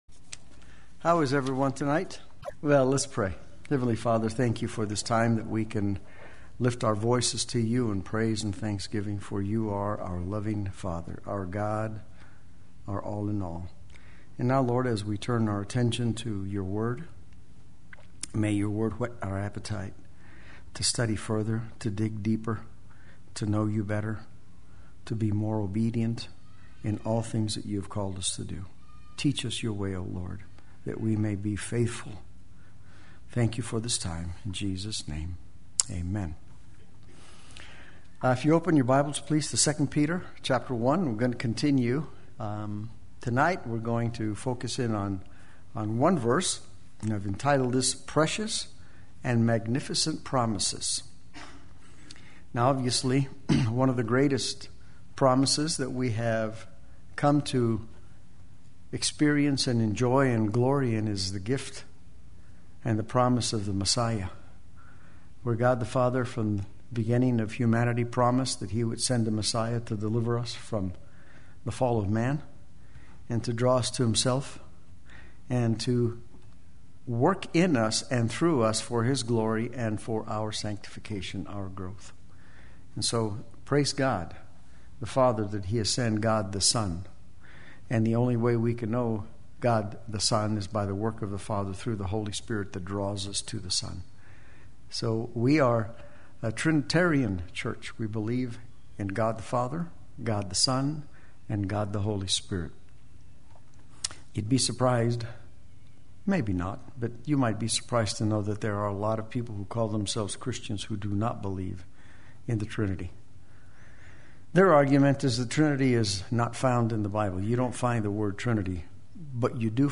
Play Sermon Get HCF Teaching Automatically.
Precious and Magnificent Promises Wednesday Worship